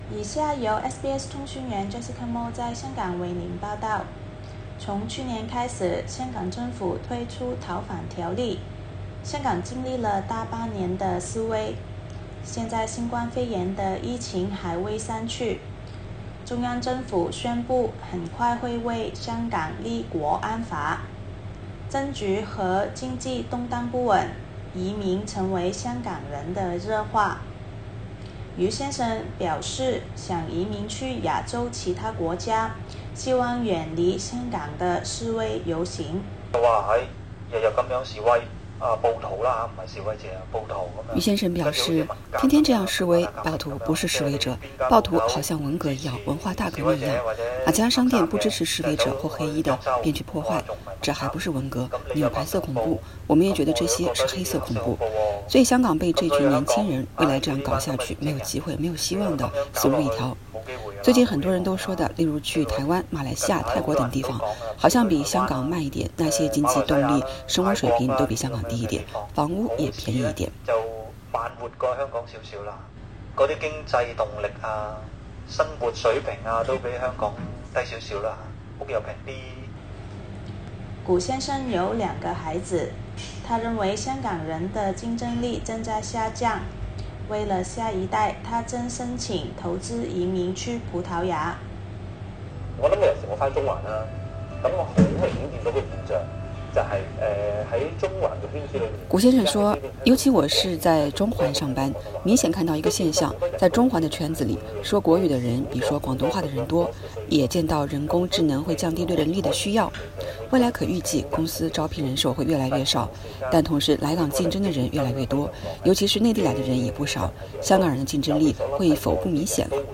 政局和经济动荡不稳，移民成为香港人的热话。SBS普通话节目对话了五名香港市民，他们未来会考虑何去何从？